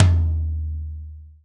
TOM TOM100.wav